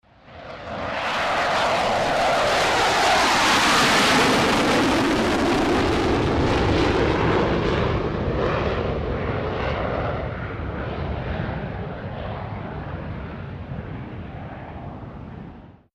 F-18 hornet jet fighter, take off